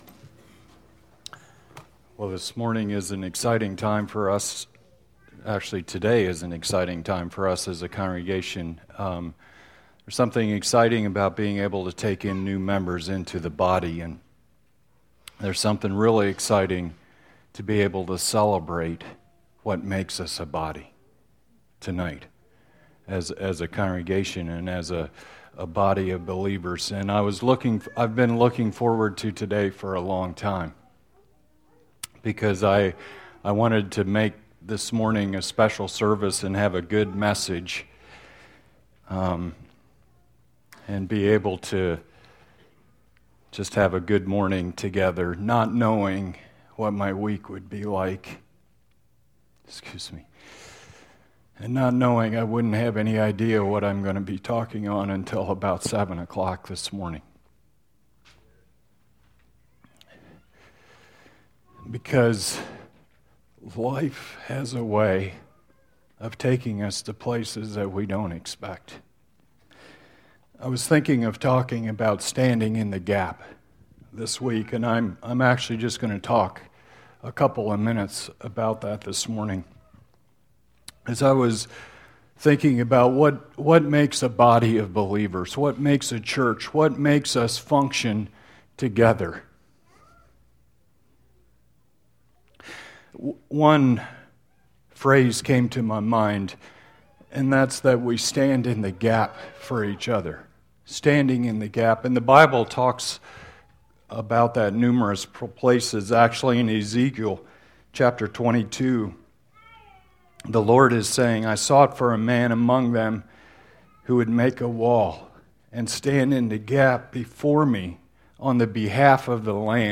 Standing In The Gap New Members Service